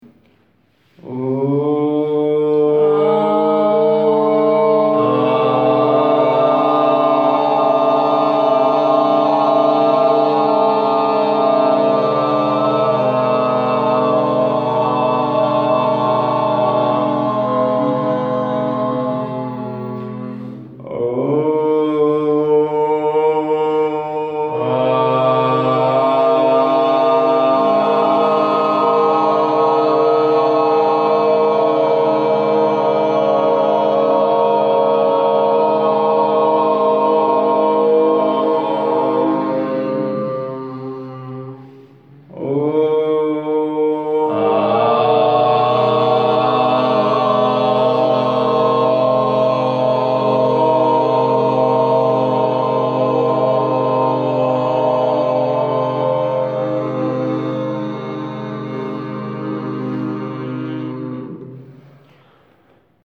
Project C: Experiment with Aum Chanting